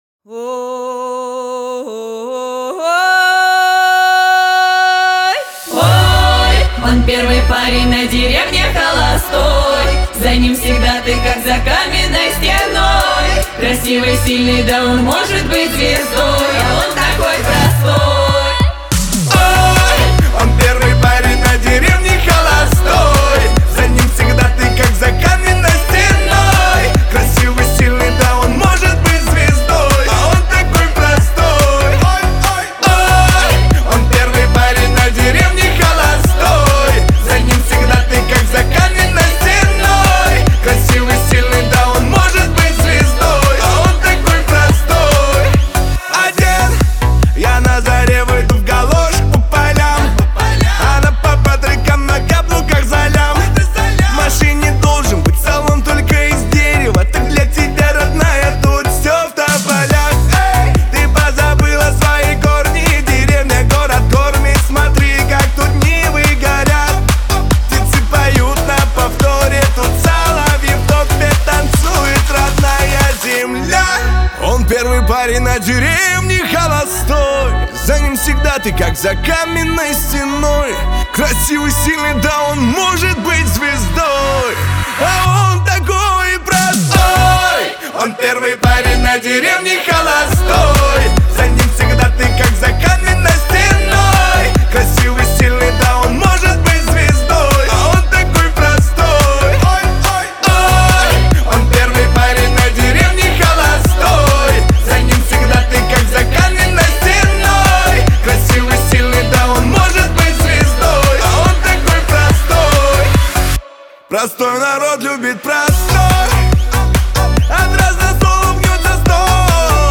Жанр:Русские новинки / OSTСаундтреки